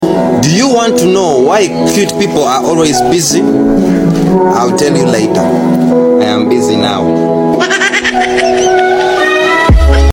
Best Motivational Speech.